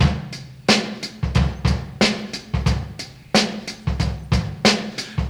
• 90 Bpm 60s Drum Groove D Key.wav
Free drum beat - kick tuned to the D note. Loudest frequency: 1715Hz